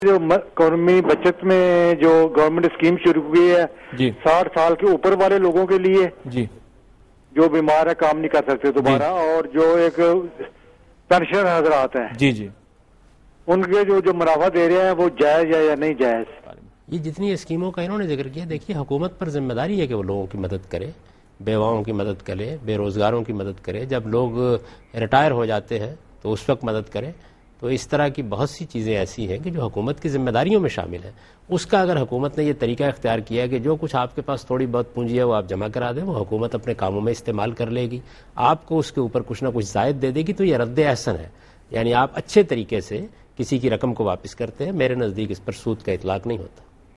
Category: TV Programs / Dunya News / Deen-o-Daanish /